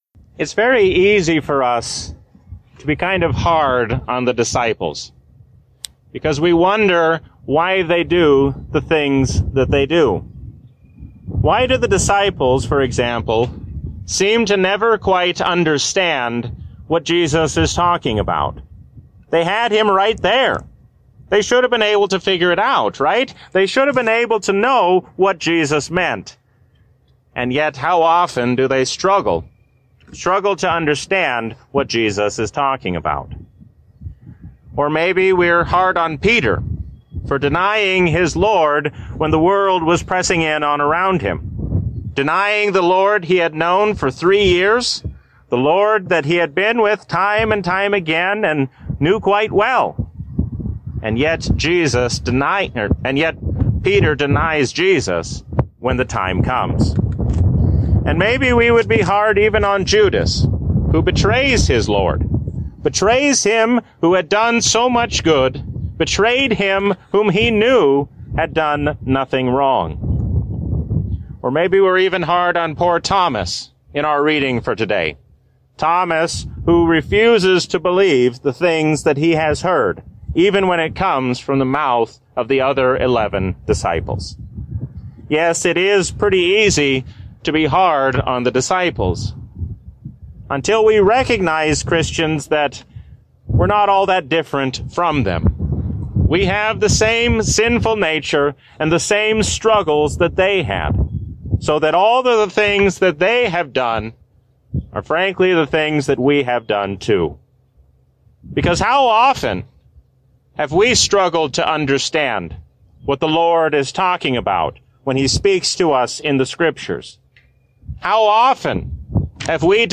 A sermon from the season "Advent 2023." We can trust all the promises of God, because we have God's favor in His Son.